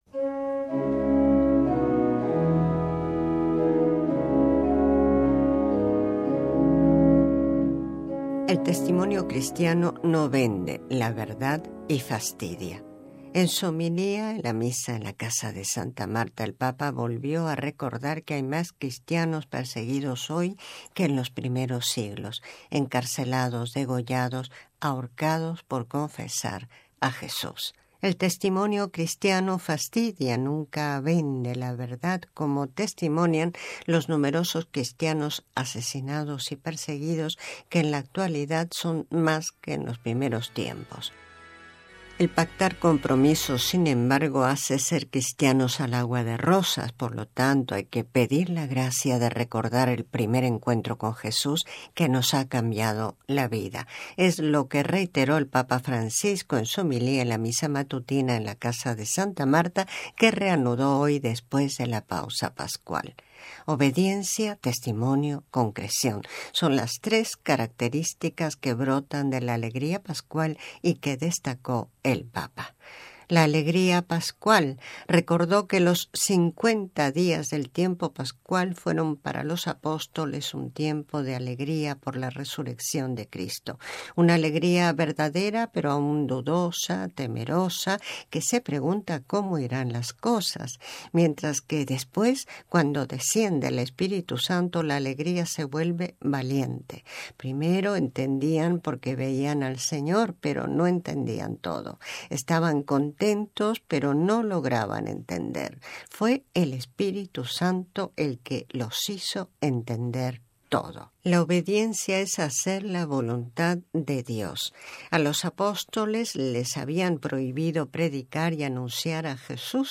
Es lo que reiteró el Papa Francisco en su homilía, en la Misa matutina, en la Casa de Santa Marta, que reanudó hoy después de la pausa pascual.
Escucha y descarga el informe con la voz del Papa